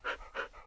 panting.ogg